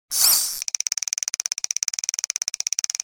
fishreel.wav